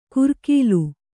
♪ kurkīlu